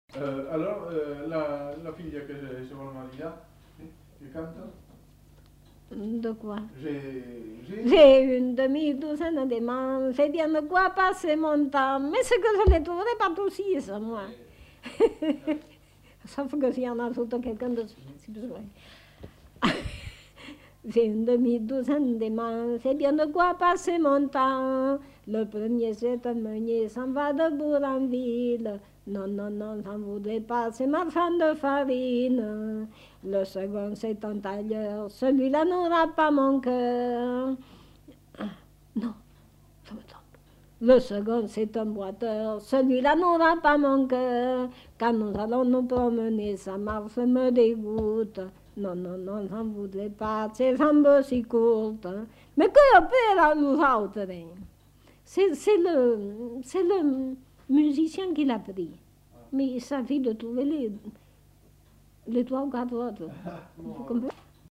Aire culturelle : Périgord
Lieu : La Chapelle-Aubareil
Genre : chant
Effectif : 1
Type de voix : voix de femme
Production du son : chanté